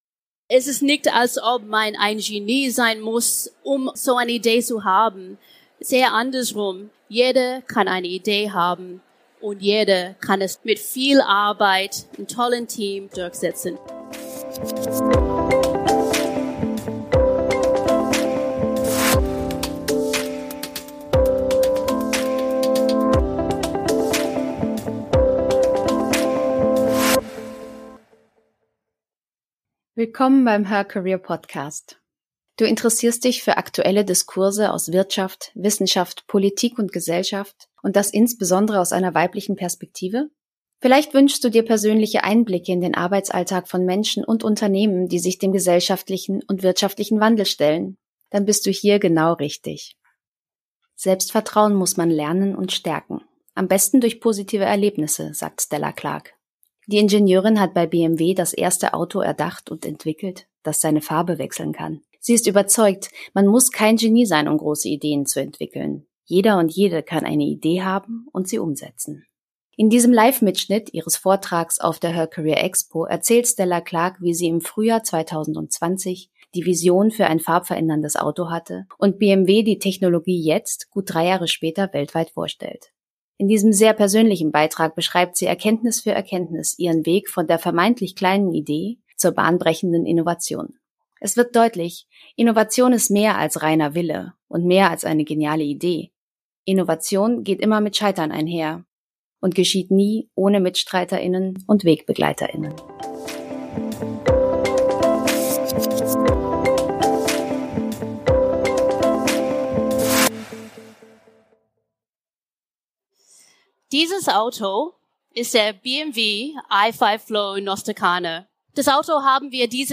Die Audio-Aufnahme entstand während einer Live-Präsentation.